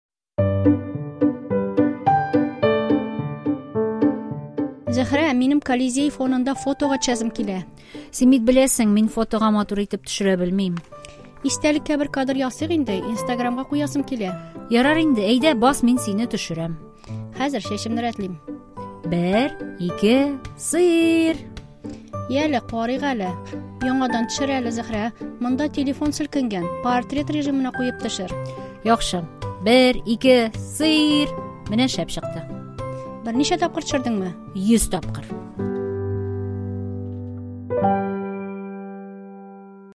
Диалог: Фотога төшәсем килә